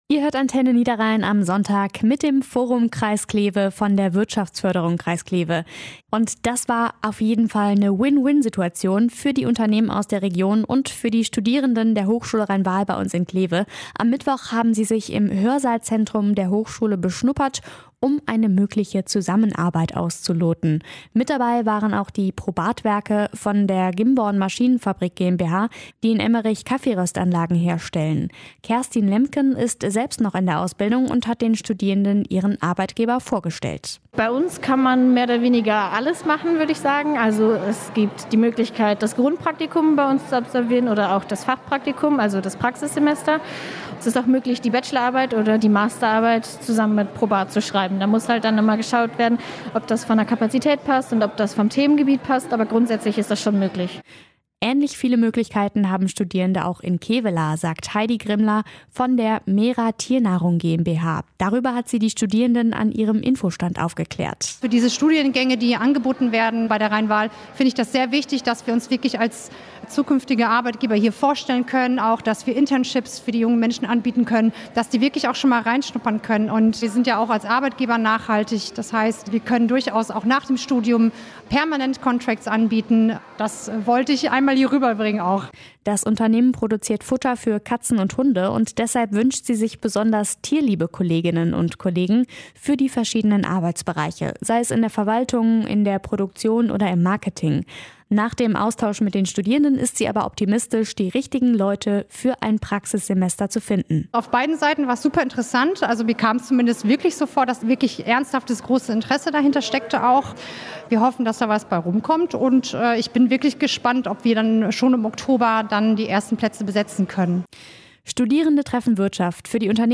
Forum Interview 4